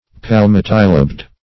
Search Result for " palmatilobed" : The Collaborative International Dictionary of English v.0.48: Palmatilobed \Pal*mat"i*lobed\, a. [L. palmatus palmate + E. lobed.]
palmatilobed.mp3